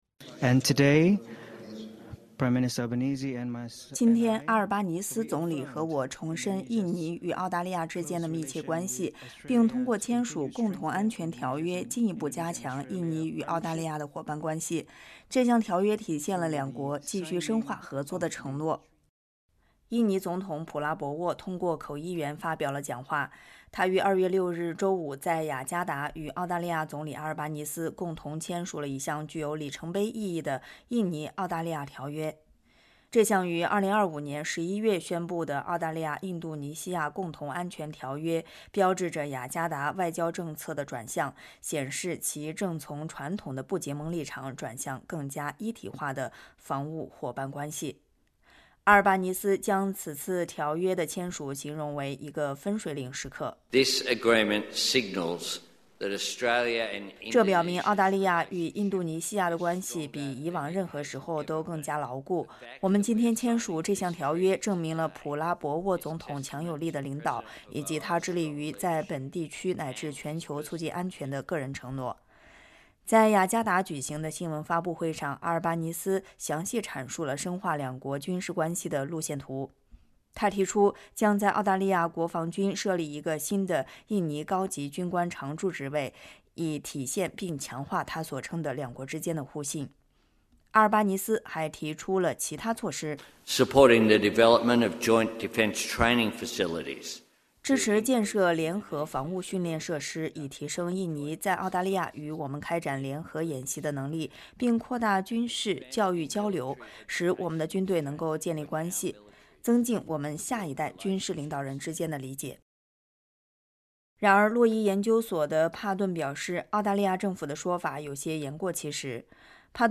印尼总统普拉博沃（Prabowo Subianto）通过口译员发表了讲话。